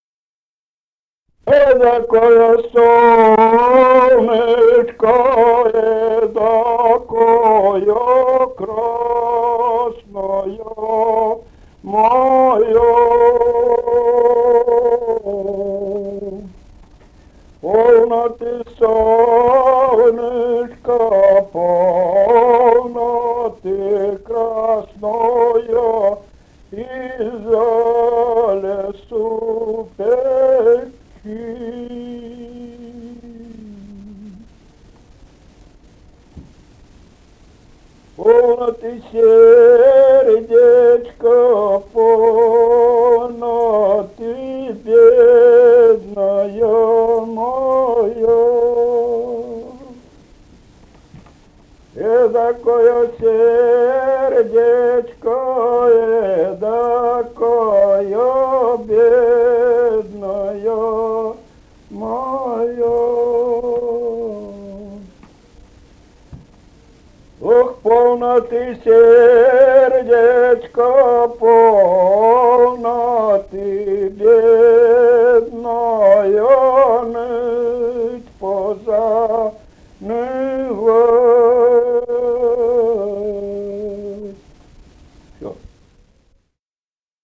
«Эдакоё солнышко» (лирическая).